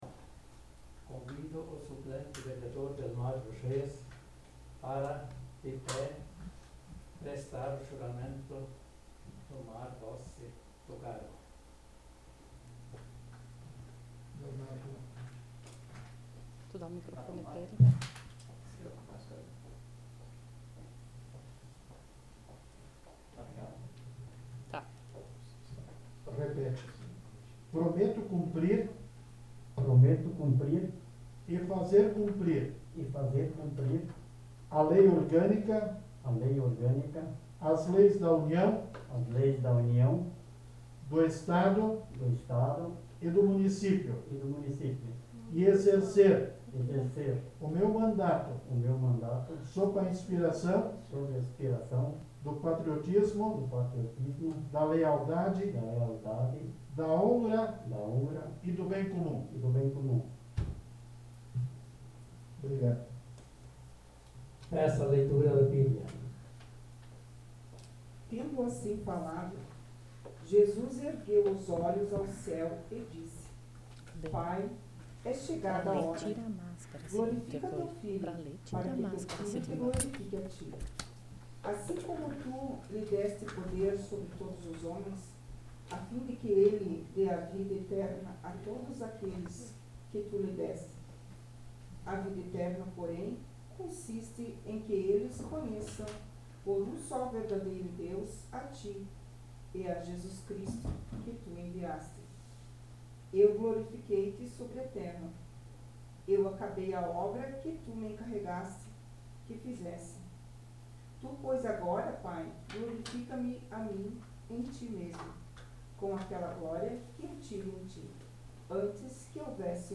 Sessão Ordinária do dia 10 de maio de 2021